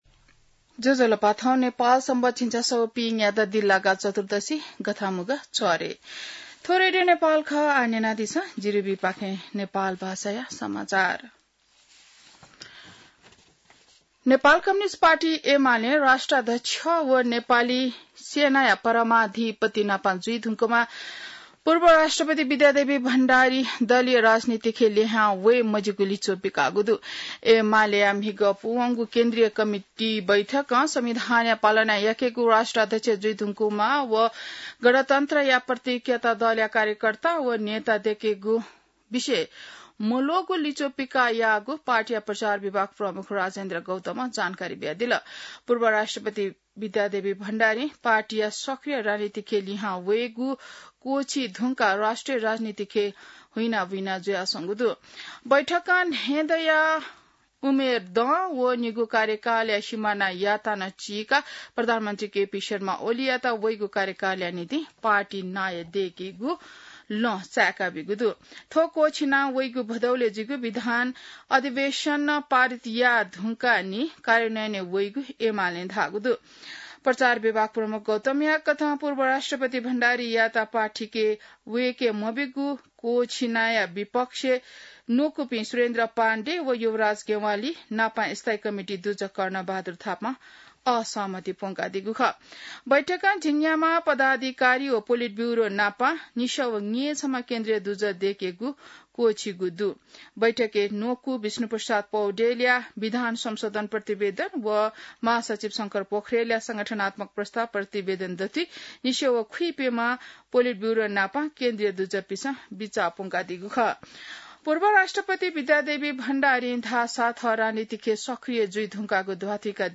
नेपाल भाषामा समाचार : ७ साउन , २०८२